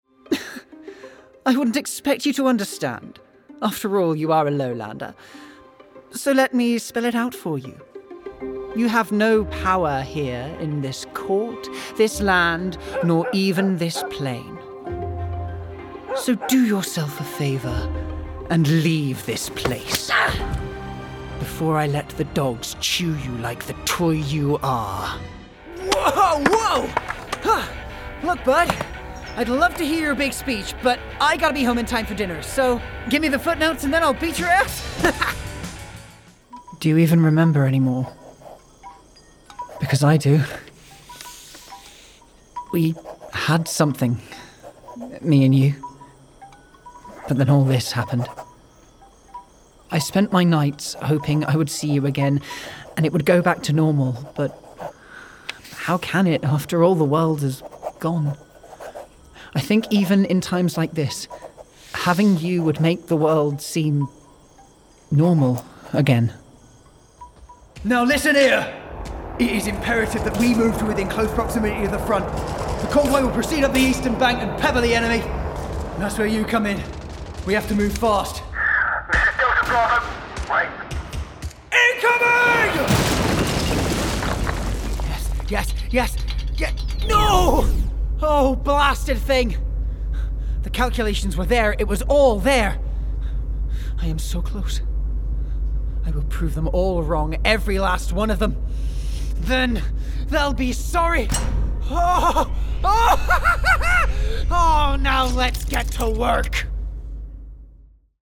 Male
Neutral British
Bright
Friendly
Playful
Upbeat